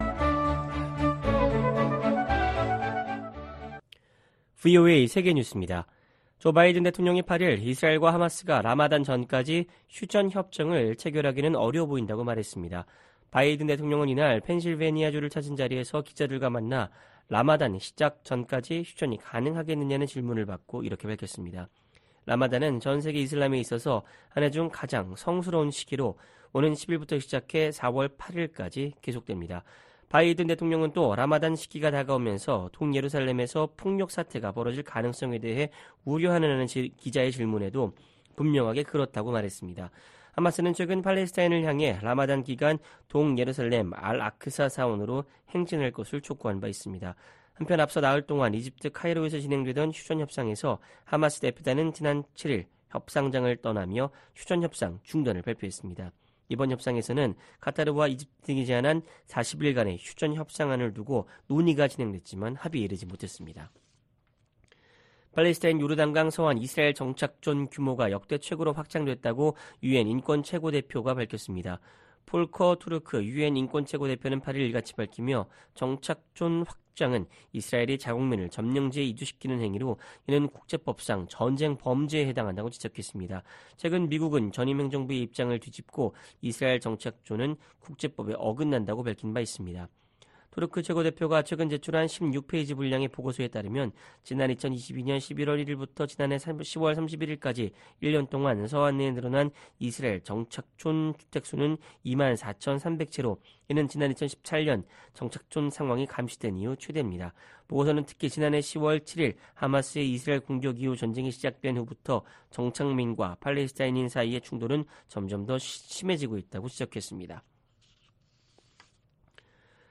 VOA 한국어 방송의 토요일 오후 프로그램 4부입니다. 한반도 시간 오후 11:00 부터 자정 까지 방송됩니다.